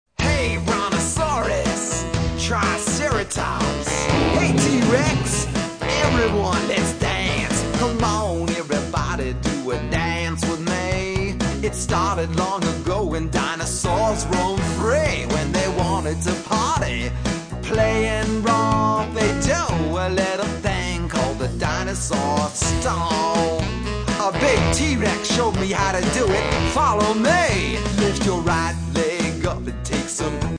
Action Song for Young Children